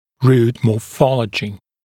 [ruːt mɔː’fɔləʤɪ][ру:т мо:’фолэджи]морфология корня